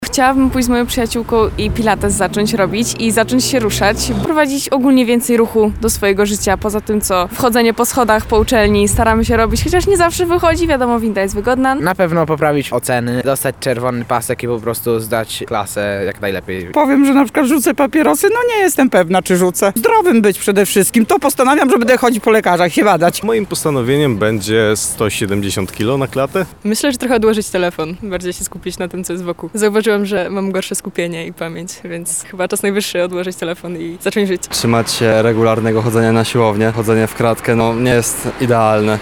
[SONDA] Nowy Rok, nowe plany – co mieszkańcy Lublina chcą zmienić w 2026 roku?
Pytamy mieszkańców Lublina o ich nadchodzące plany.
sonda
sonda-postanowienia.mp3